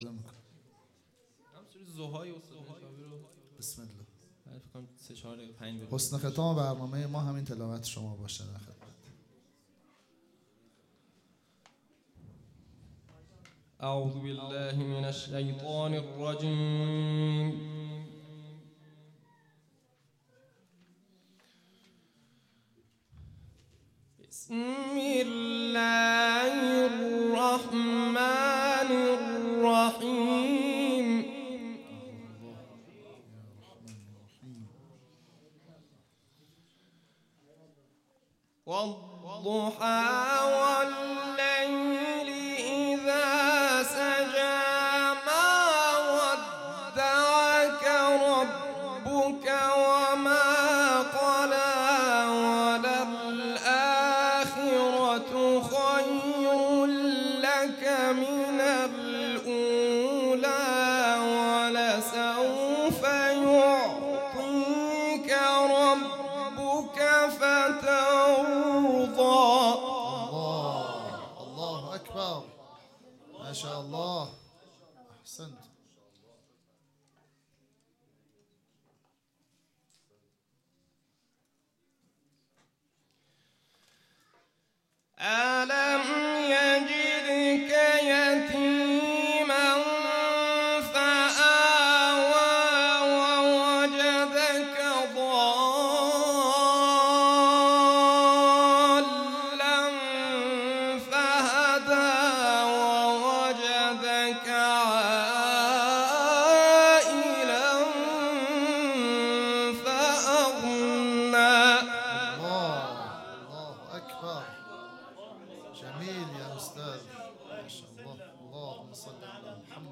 قاری قرآن